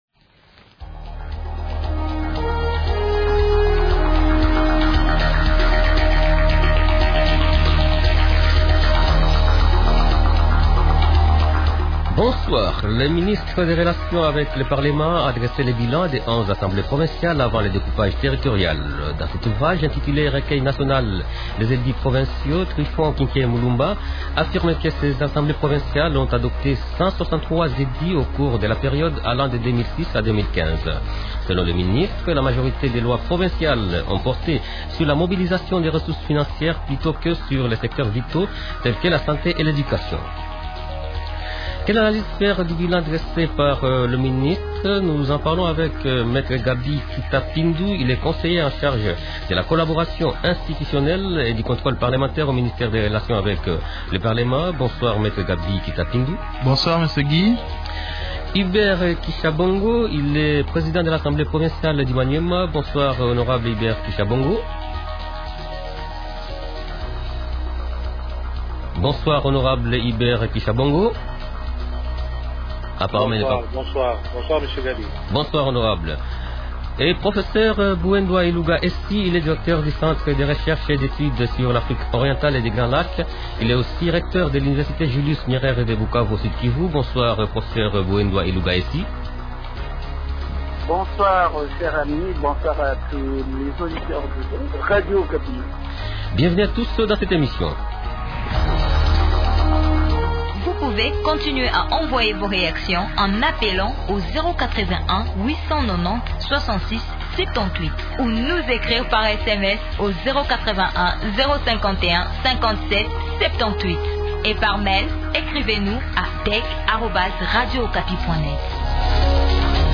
Participent au débat de ce soir :